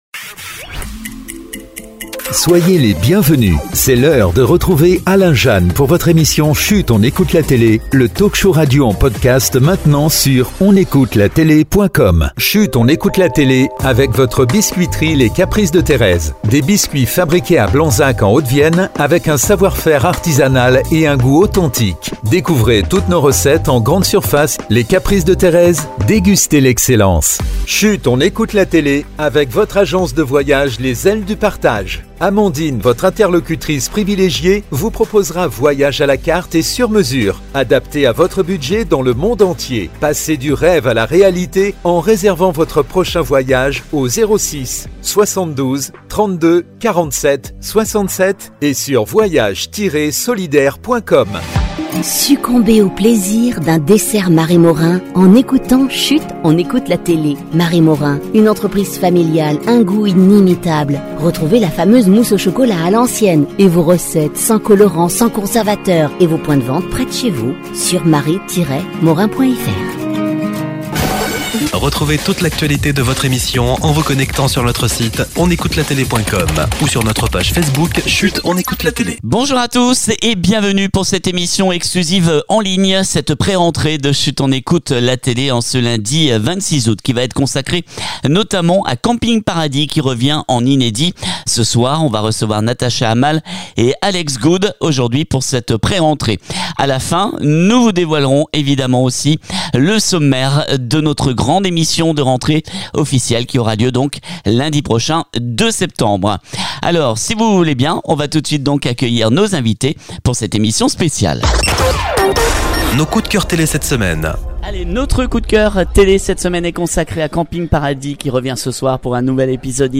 On se retrouve en ligne pour une mini émission spéciale dès ce lundi 26 Août avec Natacha Amal et Alex Goude qui nous parleront du Camping Paradis inédit diffusé ce soir sur TF1